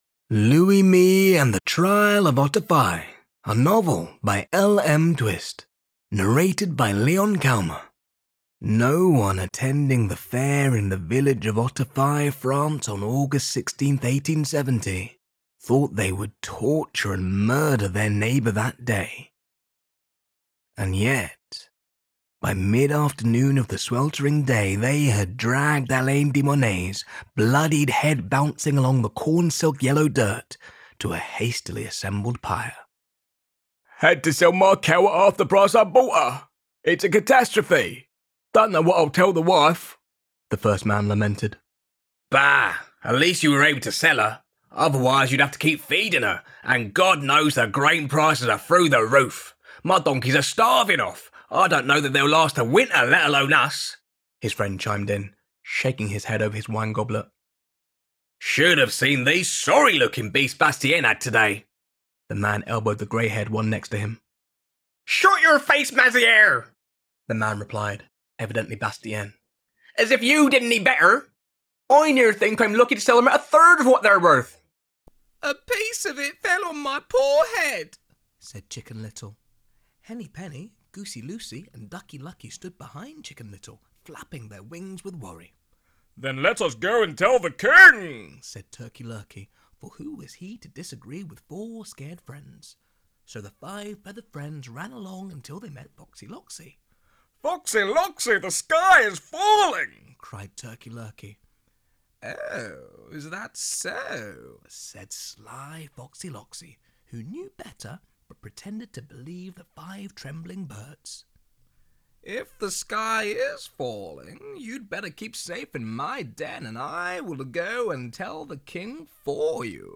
Audiobook Showreel
Male
Neutral British
Gravelly
Husky
4-audiobook-voicereel.mp3